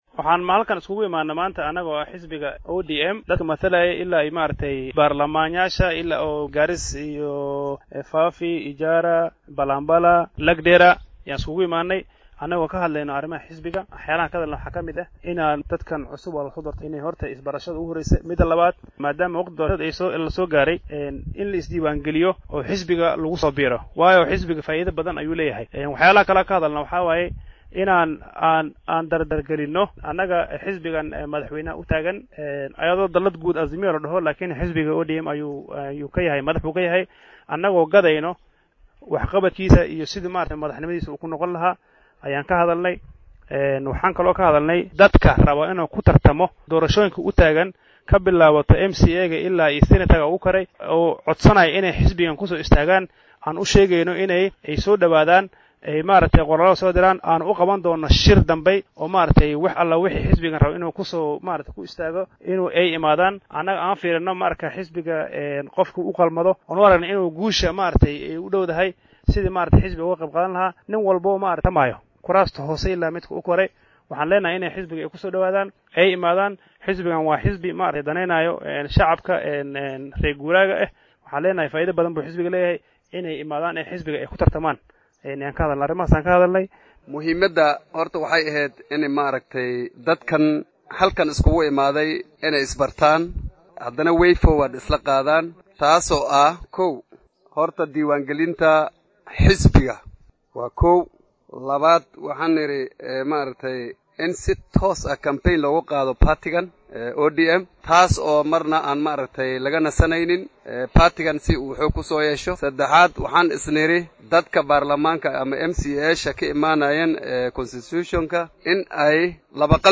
Qaar ka mid ah mas’uuliyiintan oo shir ay ku qabteen Garissa arrimahan kaga hadlay ayaa yiri.